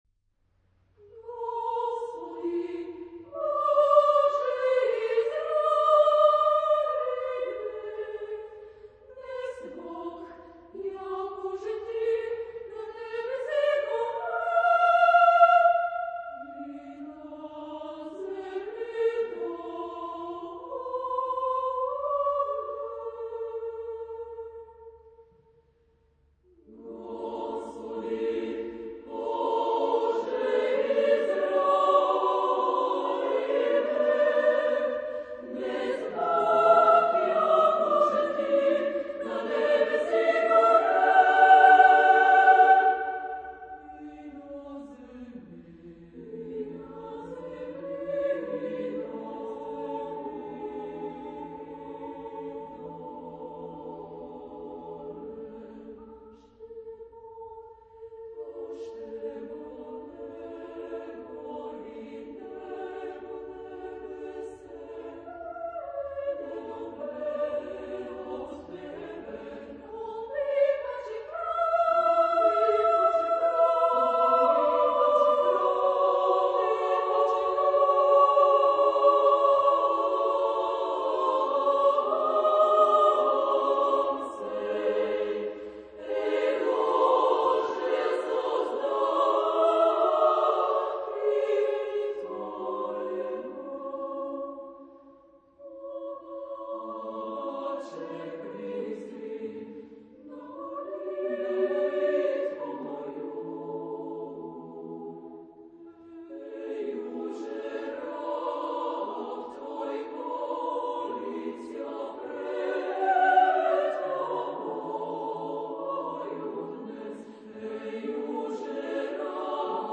Genre-Stil-Form: geistlich ; liturgische Hymne (orthodox) ; orthodox ; Liturgie ; Geistliches Concerto
Charakter des Stückes: feierlich ; majestätisch
Chorgattung: SSAATTBB  (4 gemischter Chor Stimmen )
Solisten: SATTBB  (6 Solist(en))
Aufnahme Bestellnummer: Internationaler Kammerchor Wettbewerb Marktoberdorf